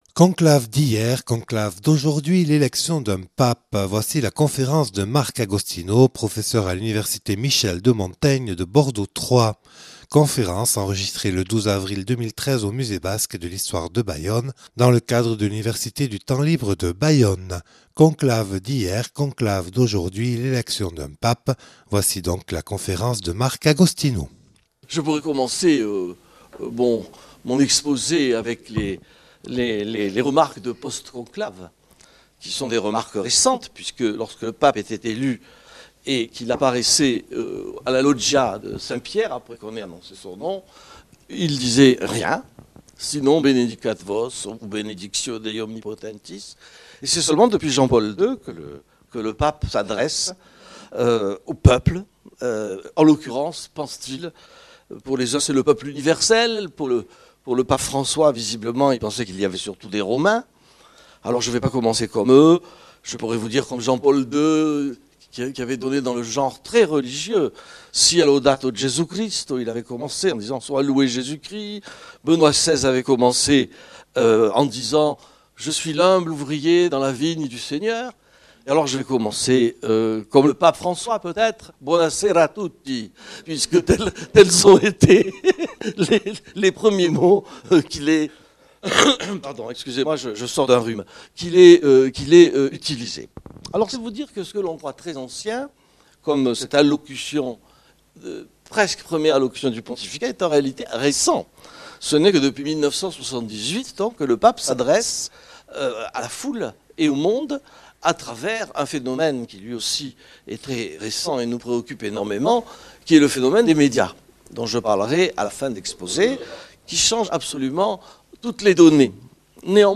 (Enregistré le 12/04/2013 au Musée Basque dans le cadre de l'Université du Temps Libre de Bayonne).